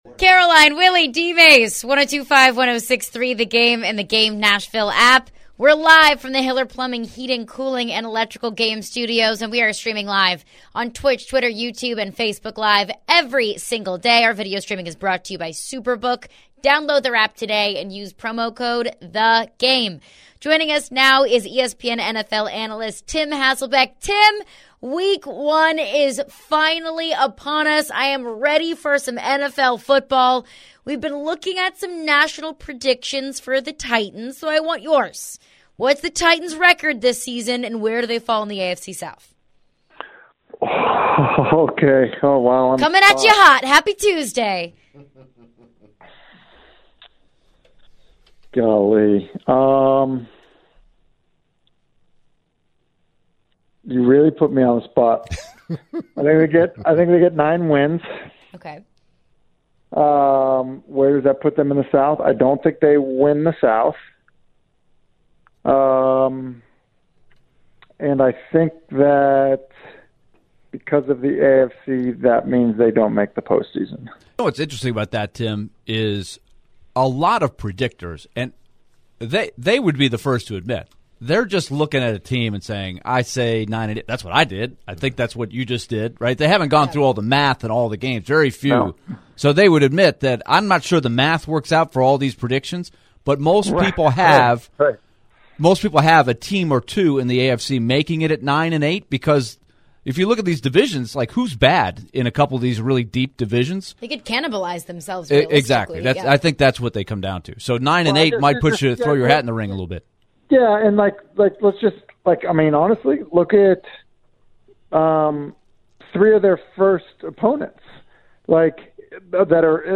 Headliner Embed Embed code See more options Share Facebook X Subscribe ESPN's Tim Hasselbeck joins the show to discuss the upcoming game for the Tennessee Titans.